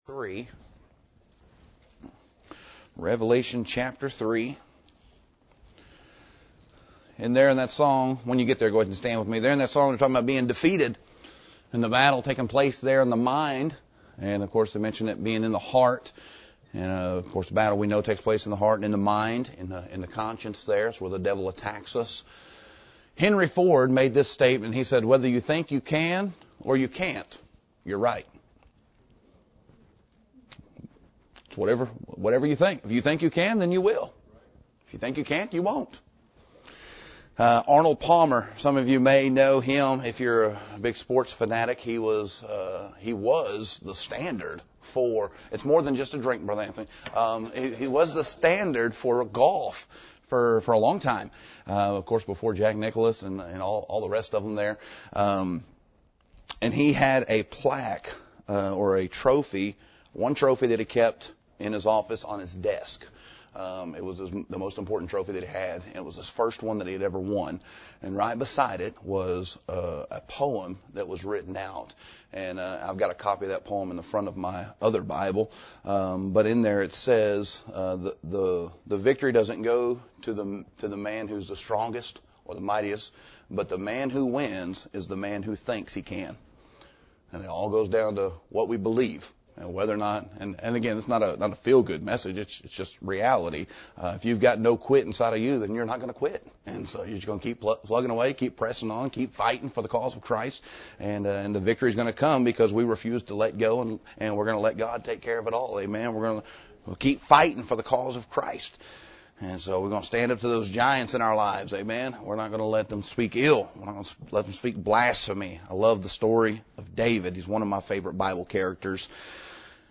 Scripture Reading — Revelation 3:14–19